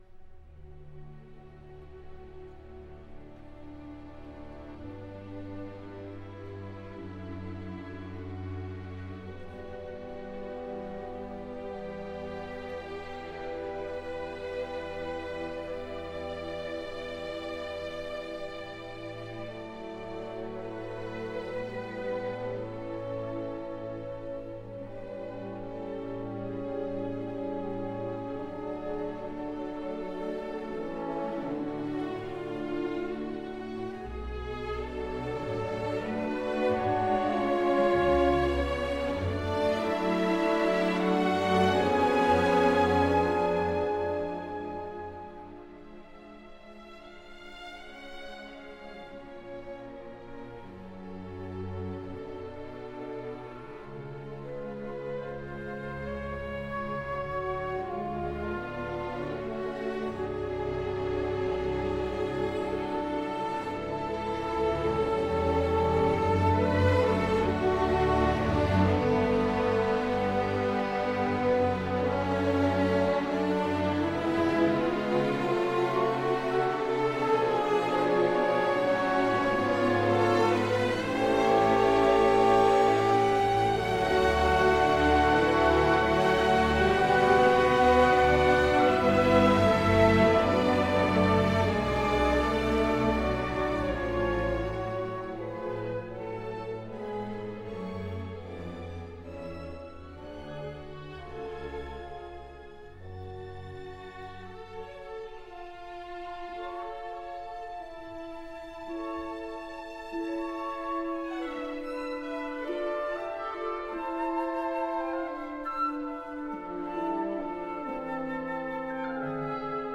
performed by Staatskapelle Berlin and Daniel Barenboim